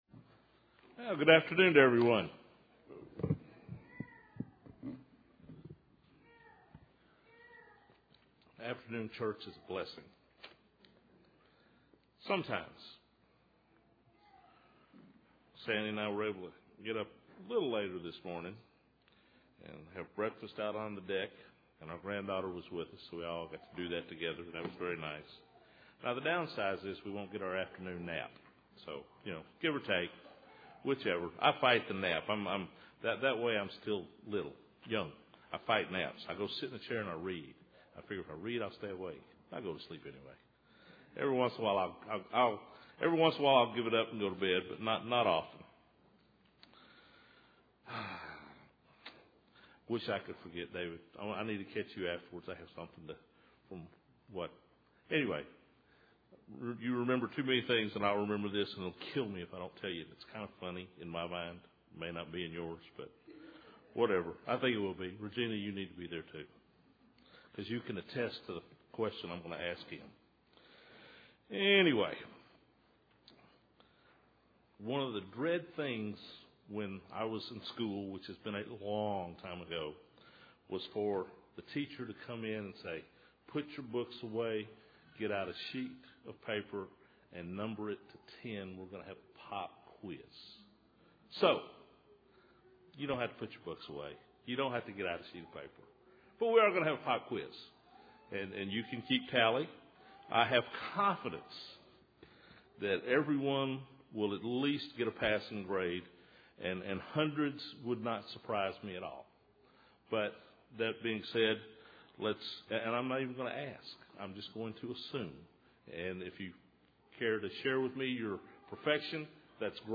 Given in Nashville, TN
Deuteronomy 17:17-19 UCG Sermon Studying the bible?